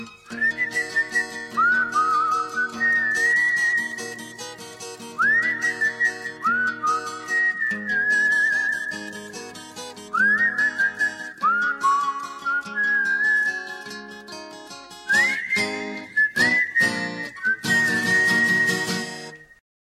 love-birds-whistle_24995.mp3